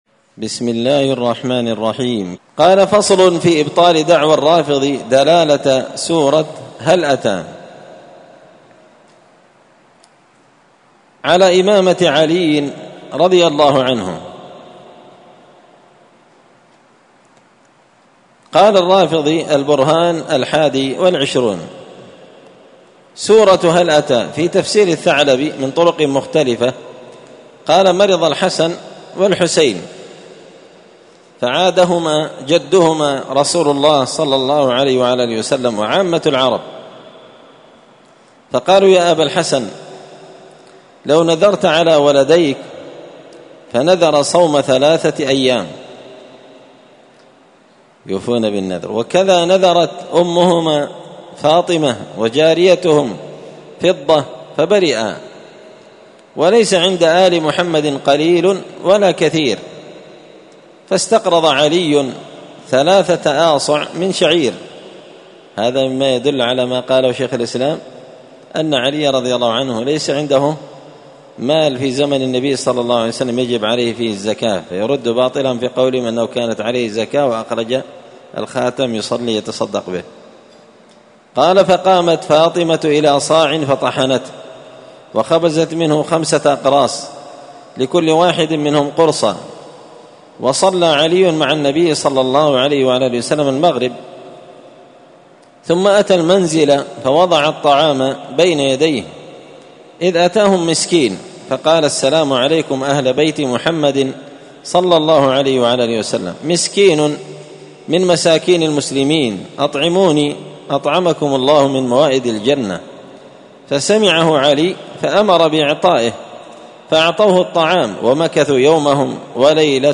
الأثنين 12 صفر 1445 هــــ | الدروس، دروس الردود، مختصر منهاج السنة النبوية لشيخ الإسلام ابن تيمية | شارك بتعليقك | 71 المشاهدات
مسجد الفرقان قشن_المهرة_اليمن